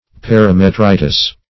Search Result for " parametritis" : Wordnet 3.0 NOUN (1) 1. inflammation of connective tissue adjacent to the uterus ; The Collaborative International Dictionary of English v.0.48: Parametritis \Par`a*me*tri"tis\, n. [NL.